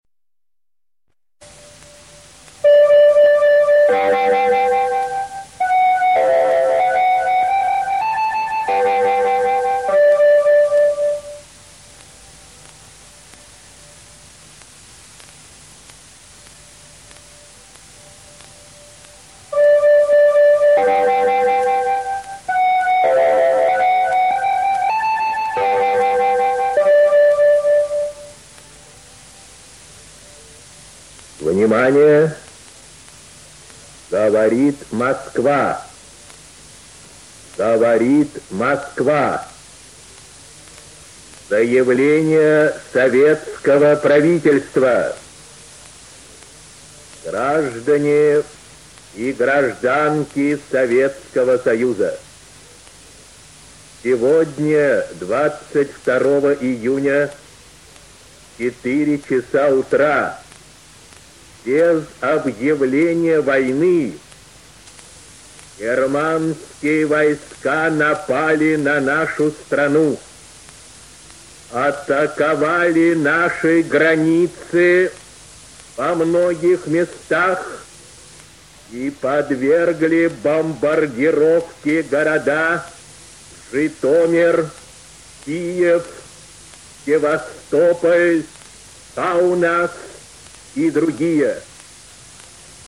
Сообщение советского радио о нападении Германии на СССР. Текст читает Ю. Левитан
levitan_ussr_attacked.mp3